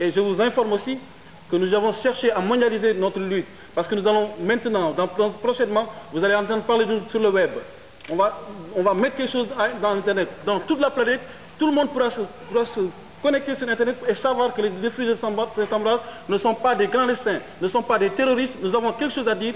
C'est une conférence de presse qui a eu lieu à la Cartoucherie de Vincennes, organisée par Ariane Mnouchkine au début du mois d'avril 96.